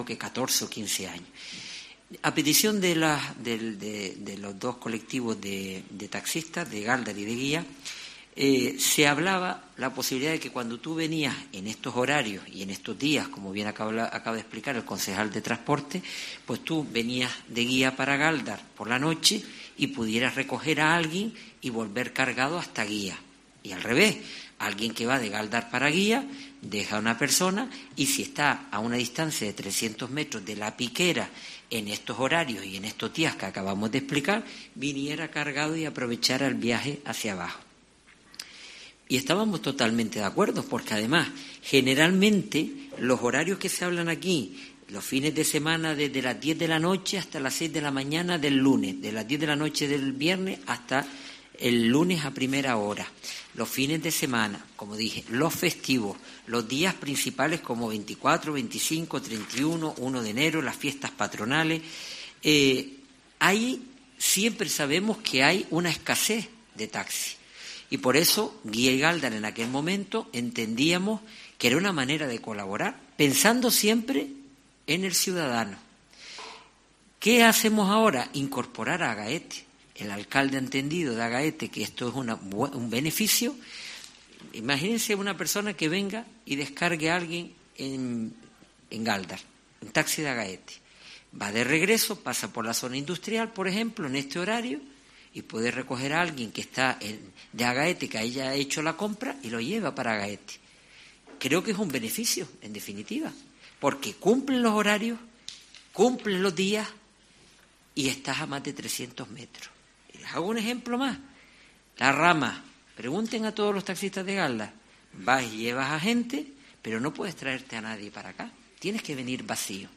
Teodoro Sosa, alcalde del municipio de Gáldar